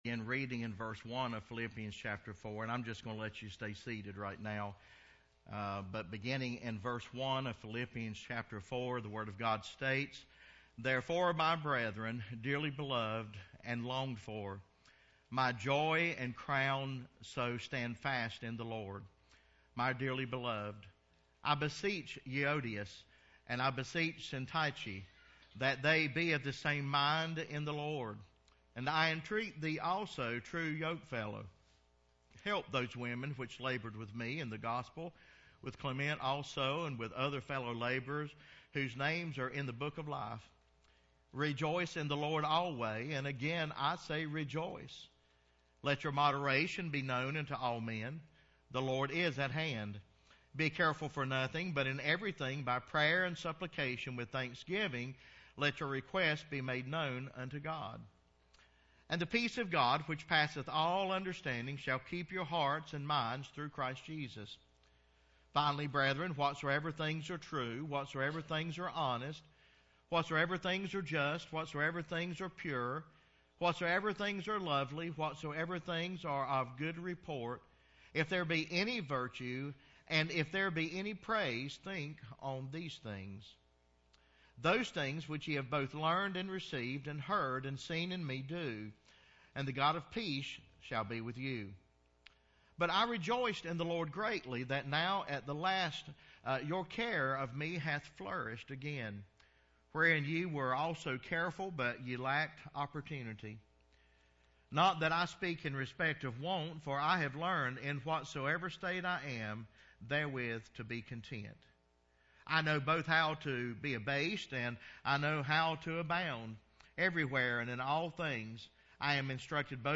Passage: Philippians 4 Service Type: Sunday Sermon